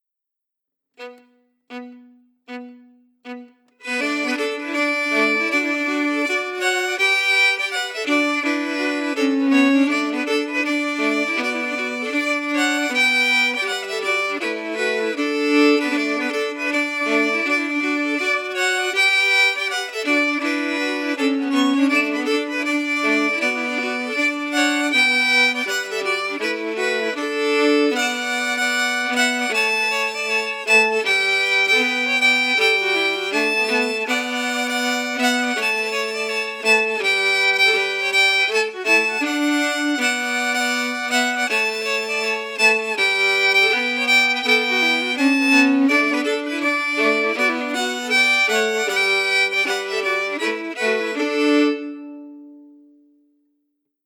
Key: D
Form:Slow reel
Harmony emphasis